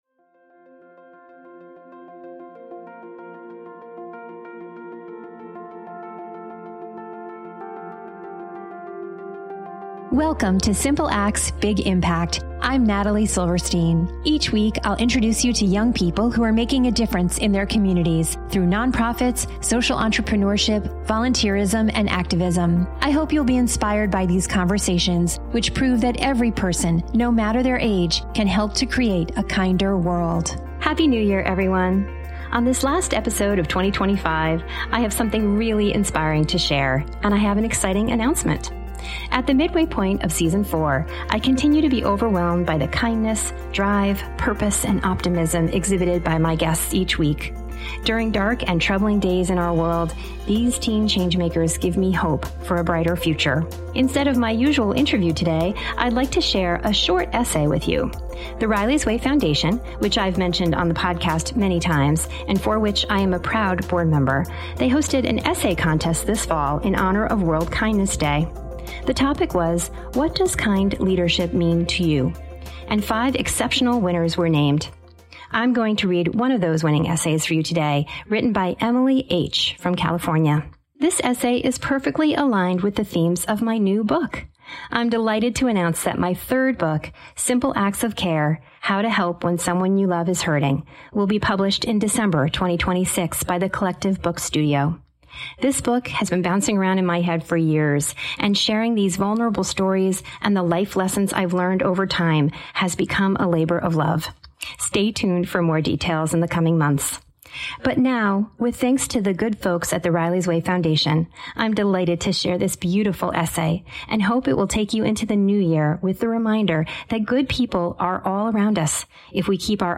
Instead of my usual interview, today, I'd like to share a short essay with you.